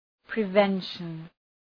{prı’venʃən}
prevention.mp3